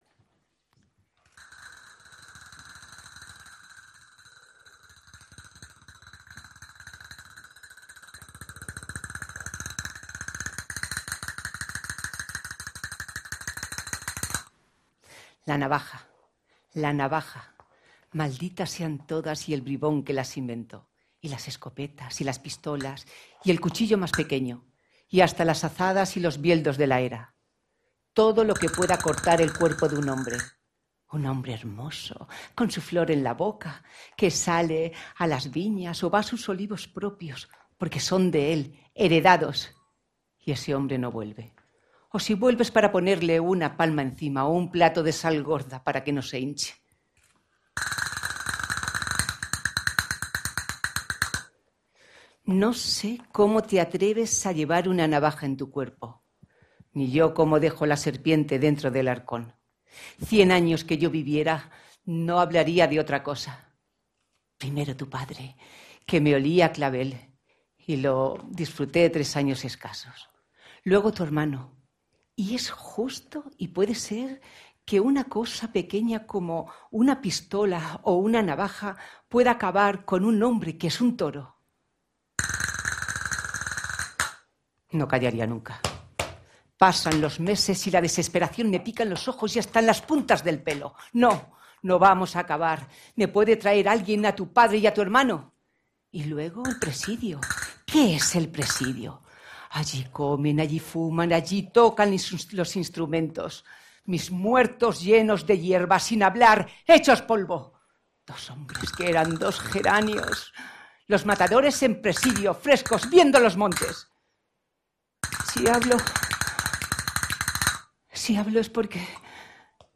intervino con la lectura en braille de un